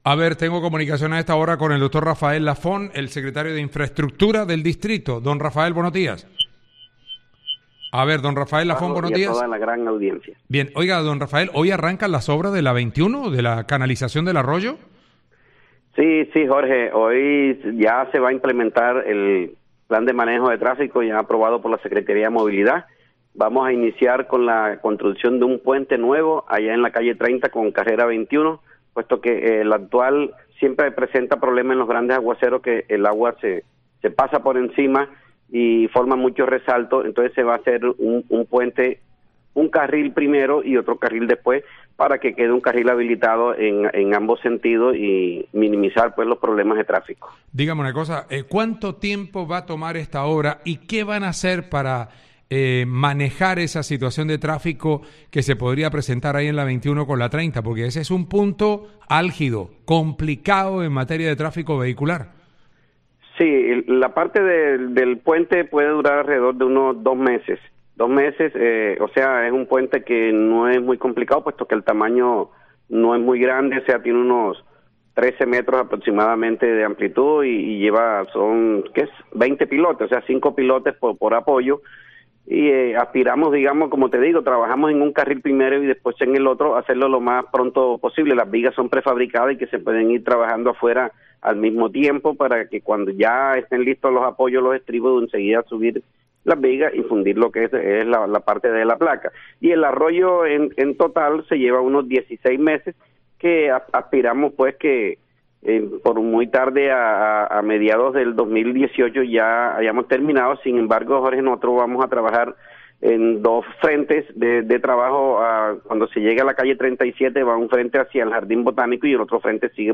Así lo confirmó el secretario de Infraestructura del Distrito, Rafael Lafont, quien indicó que se intervendrá primero un carril, para evitar generar mayores traumatismos en la movilidad.